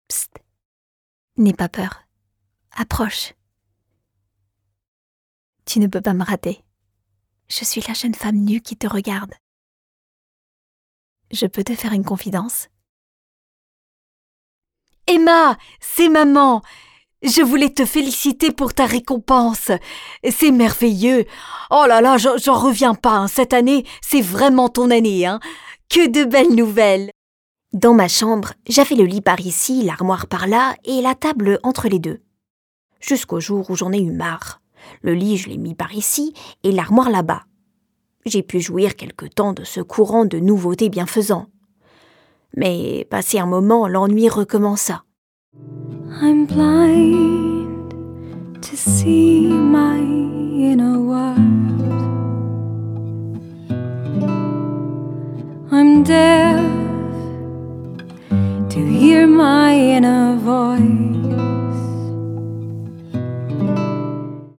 Comédienne voix off bilingue: Un sourire dans une voix…
Jouée / chantée
Ma voix peut être rassurante, convaincante, chaleureuse, sensuelle et séduisante, mais aussi pétillante, enjouée et avec une tonalité enfantine.